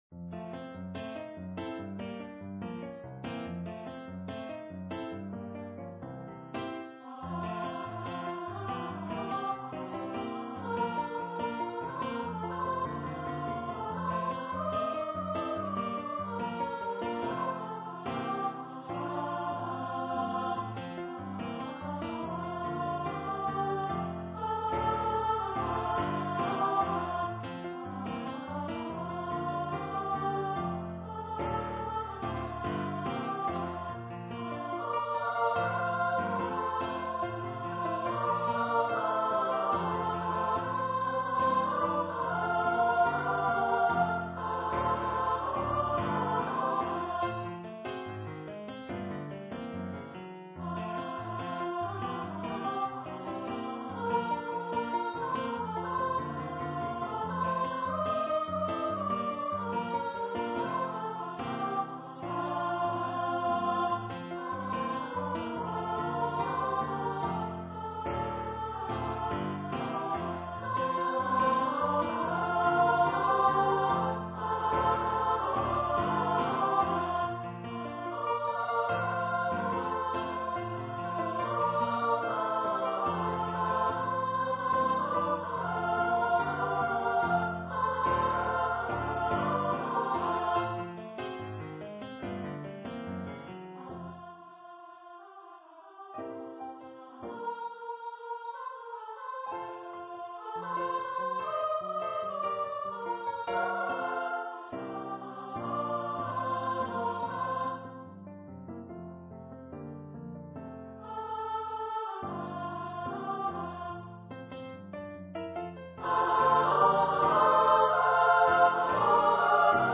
for female voice choir